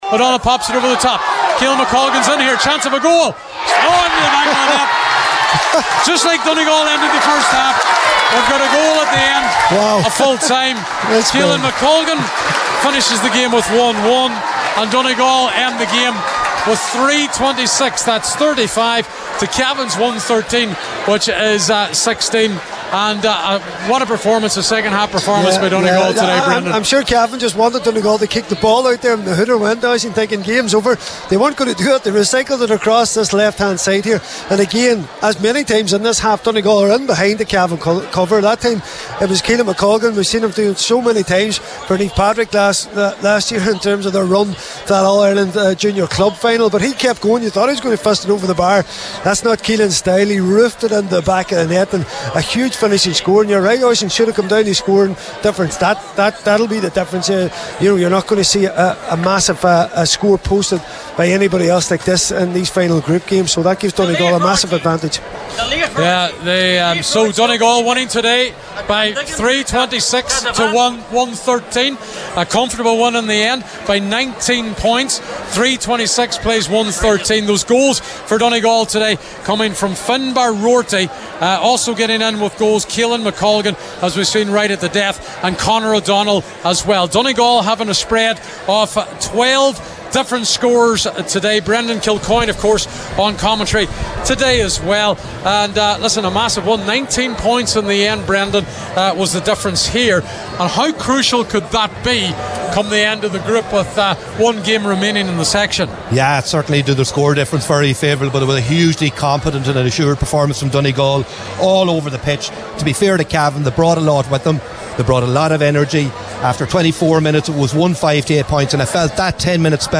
Post-Match Reaction
were live at full time in Cavan…